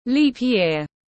Năm nhuận tiếng anh gọi là leap year, phiên âm tiếng anh đọc là /ˈliːp ˌjɪər/
Leap-year-.mp3